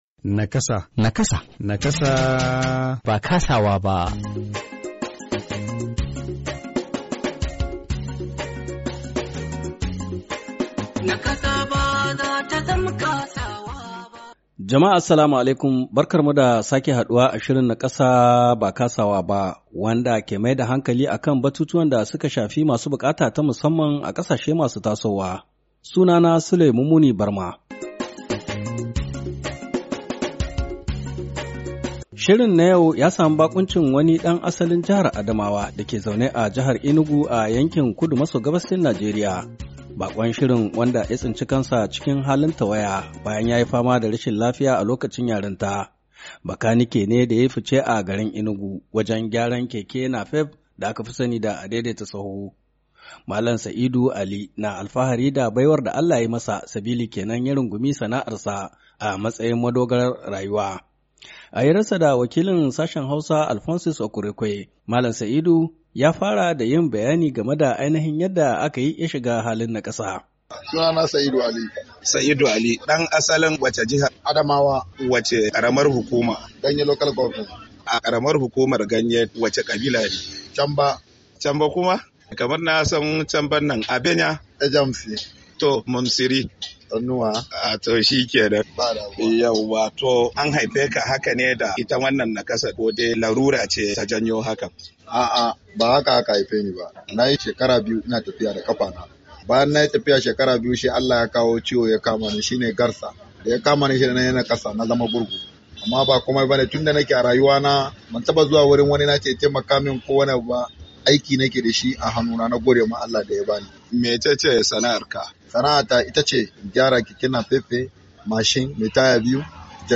NAKASA BA KASAWA BA: Hira Da Wani Mai Larurar Tawaya Da Ke Sana’ar Kanikanci, Yuni 22, 2023